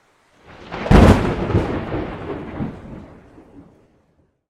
thunder_20.ogg